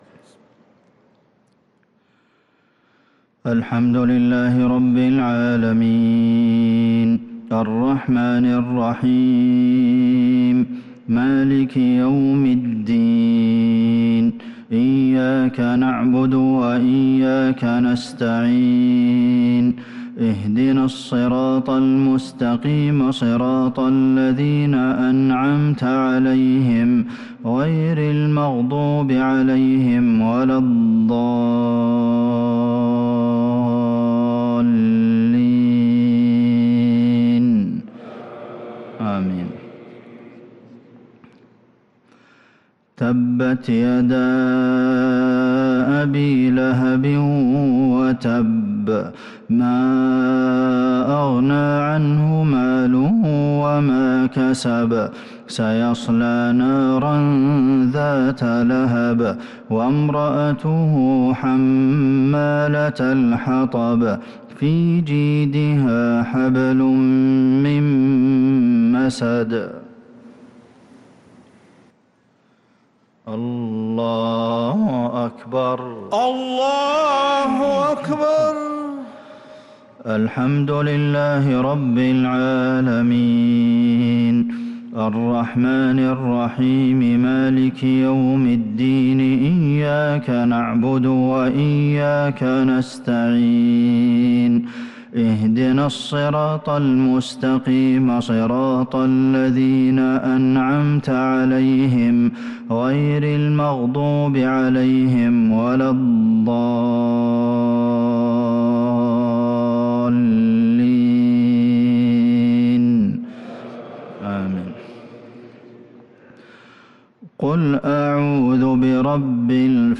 صلاة المغرب للقارئ عبدالمحسن القاسم 26 شوال 1443 هـ
تِلَاوَات الْحَرَمَيْن .